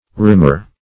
Rimmer \Rim"mer\